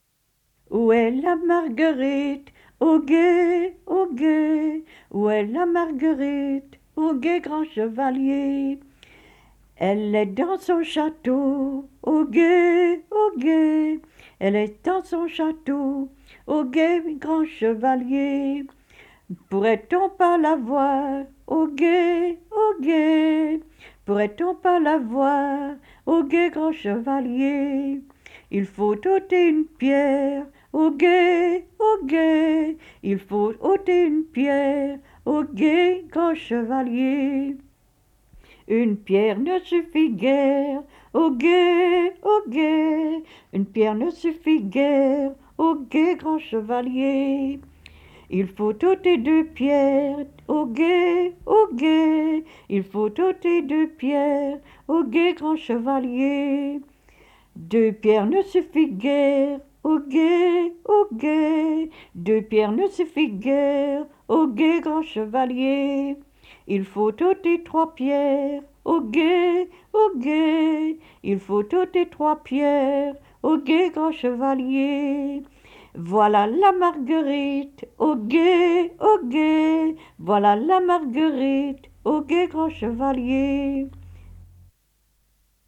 Genre : chant
Type : chanson d'enfants
Lieu d'enregistrement : Vottem
Support : bande magnétique